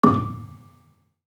Gambang-D5-f.wav